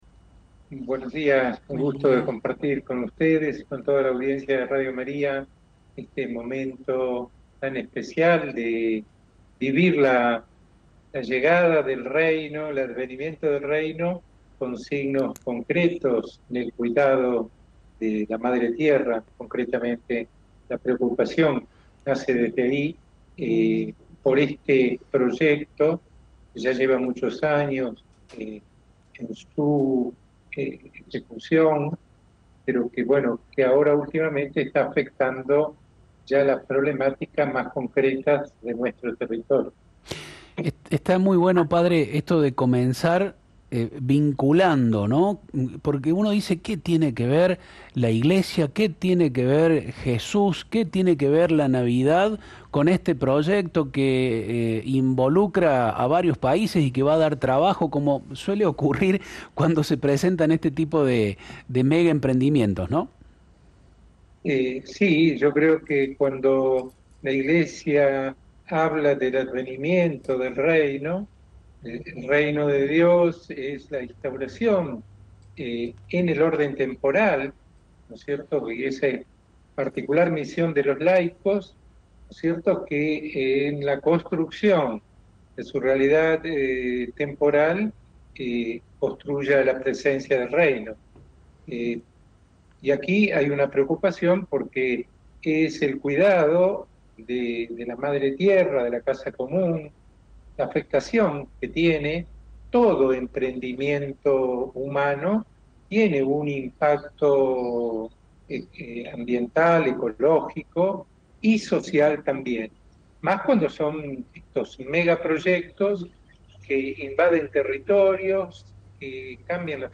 La postura de la Iglesia de Orán, que se profundizará en una conversación con Monseñor Scozzina, no es de oposición al desarrollo, sino de llamado a la responsabilidad y a la transparencia.